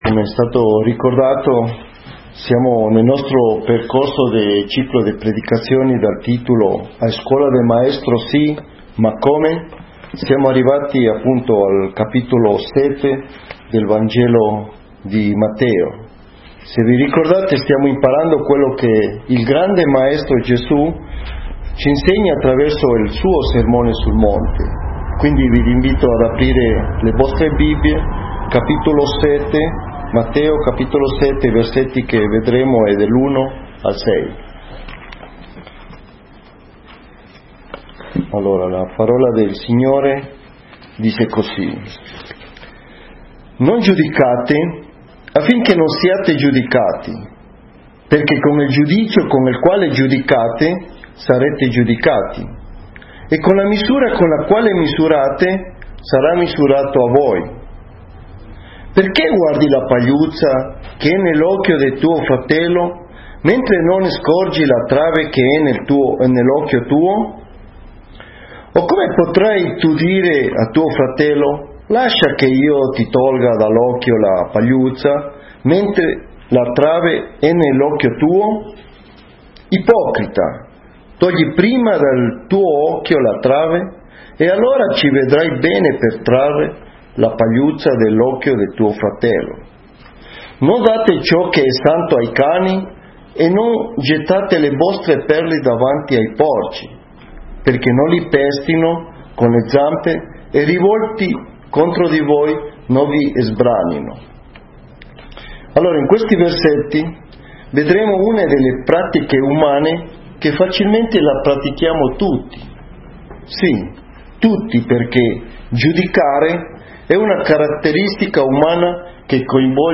Genere: Predicazione.